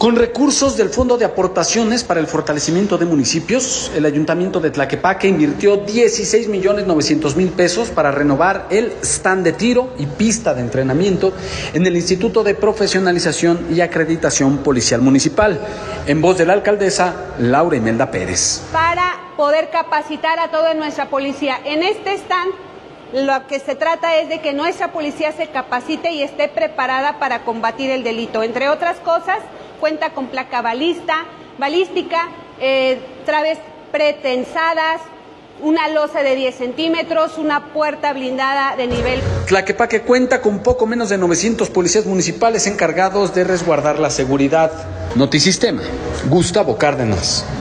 Con recursos del Fondo de Aportaciones para el Fortalecimiento de Municipios, el Ayuntamiento de Tlaquepaque invirtió 16 millones 900 mil pesos para renovar el stand de tiro y pista de entrenamiento en el Instituto de Profesionalización y Acreditación Policial Municipal. En voz de la alcaldesa Laura Imelda Pérez.